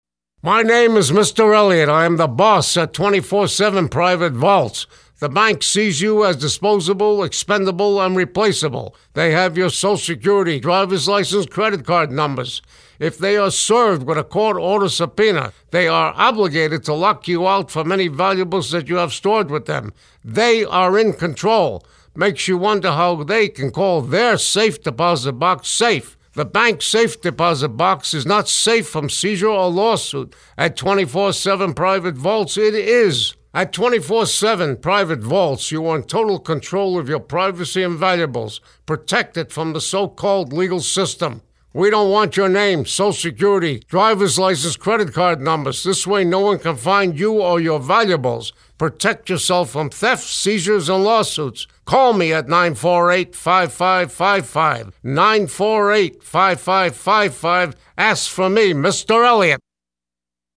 Accentuate the Positives: Client-Voiced Radio Commercials
Here’s another successful radio advertiser whose distinctive individual style—obviously ethnic, a little rough around the edges, and in-your-face—creates a compelling commercial for his Las Vegas-based company: